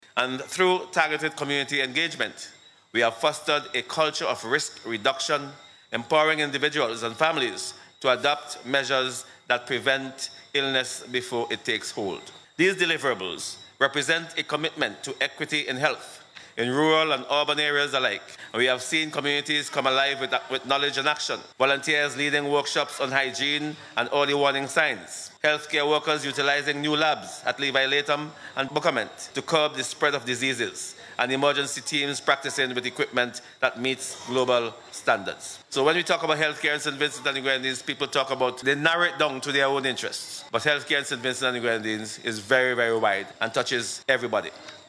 Speaking at the recent closing of the Taiwan-funded Public Health Emergency Response System Enhancement Project, Prince hailed the initiative as a symbol of health equity and reminded citizens that healthcare in SVG reaches far beyond individual concerns and touches every aspect of national life.